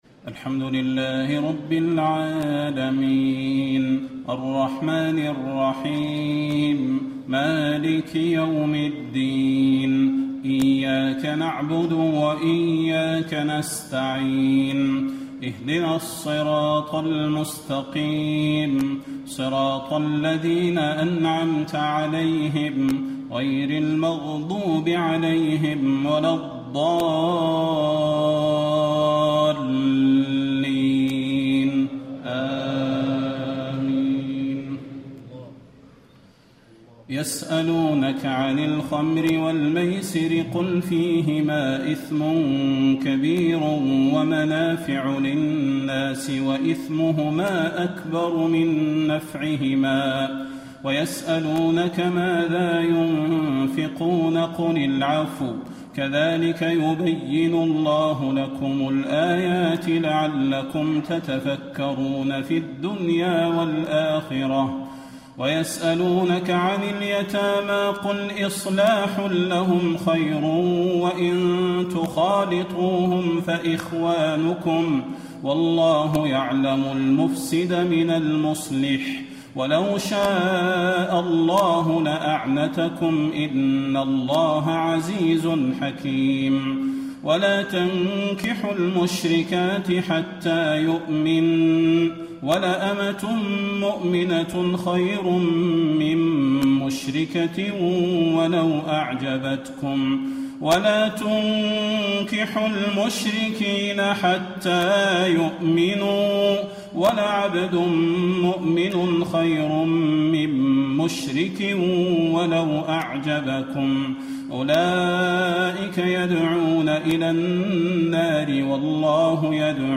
تهجد ليلة 22 رمضان 1435هـ من سورة البقرة (219-252) Tahajjud 22 st night Ramadan 1435H from Surah Al-Baqara > تراويح الحرم النبوي عام 1435 🕌 > التراويح - تلاوات الحرمين